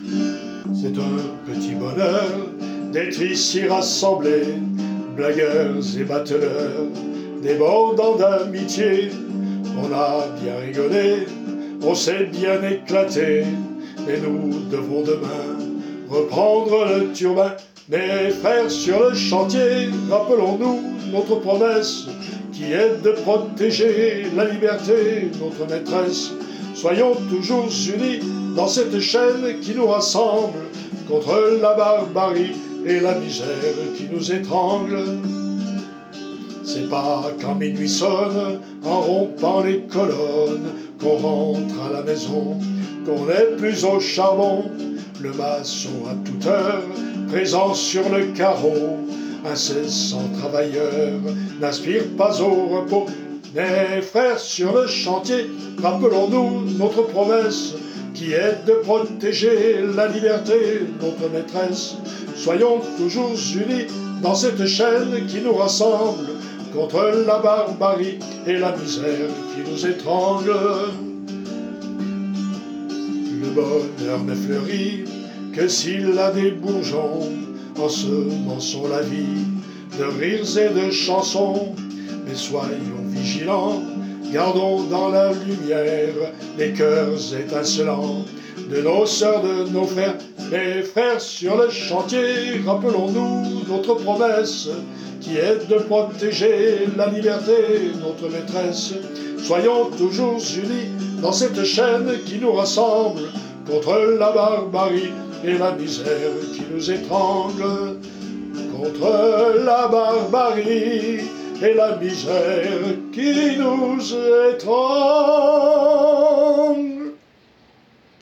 Enregistrement solo